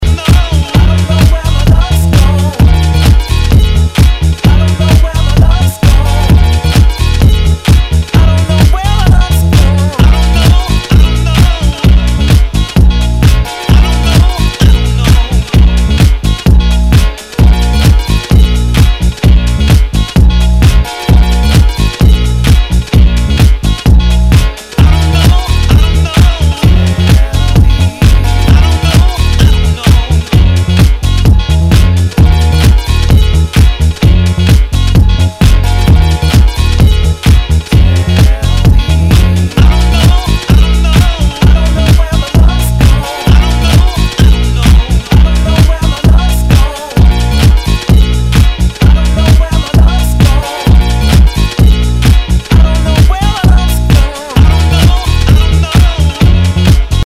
HOUSE/TECHNO/ELECTRO
ナイス！フレンチ・ヴォーカル・ハウス！